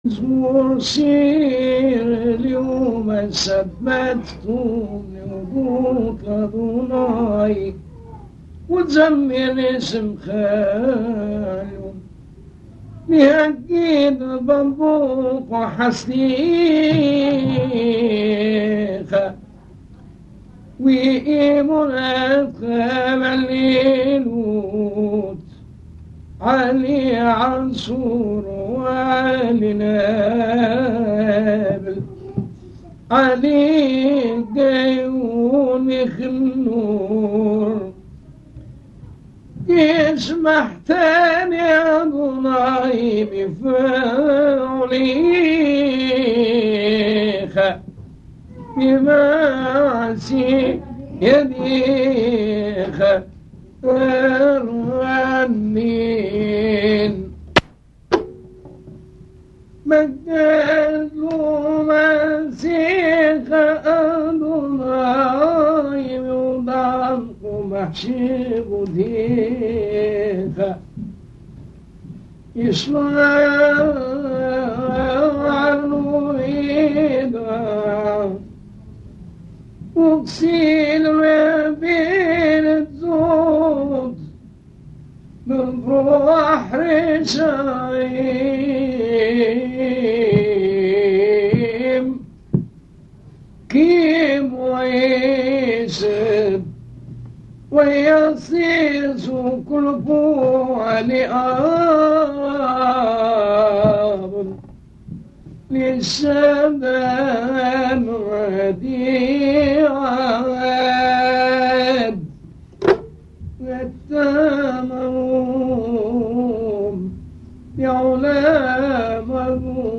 Lecture de "Mizmor Chir LeYom HaShabbath"